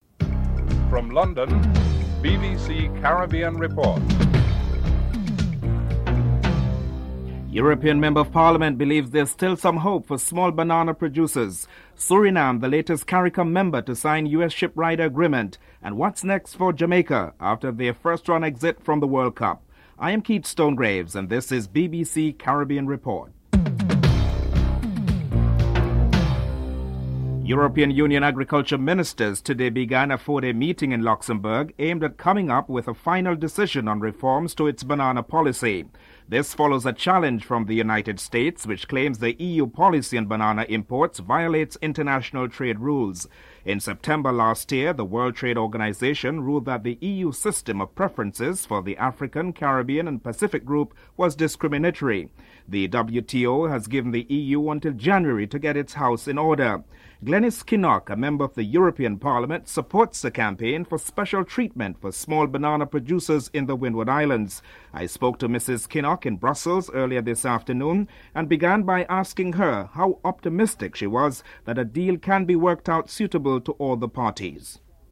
1. Headlines (00:00-00:26)
Glenys Kinnock comments on whether a suitable deal can be reached for all parties (00:27-03:30)